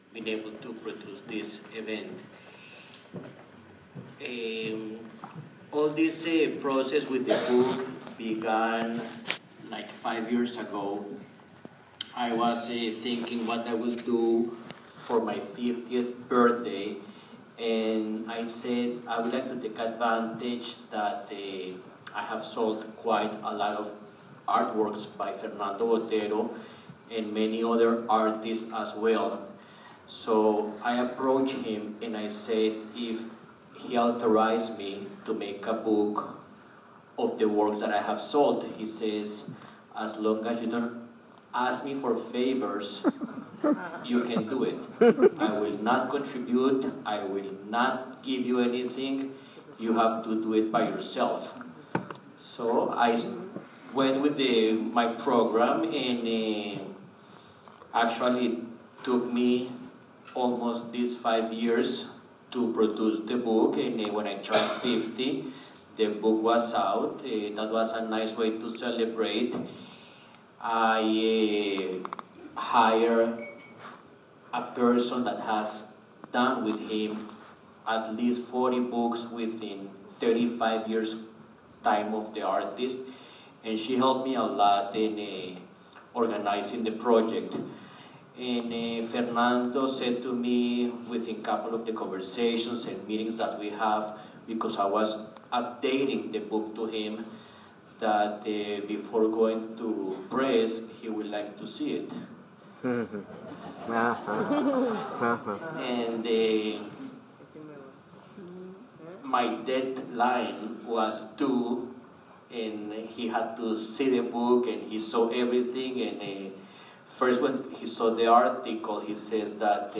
Everyone in the audience received a copy.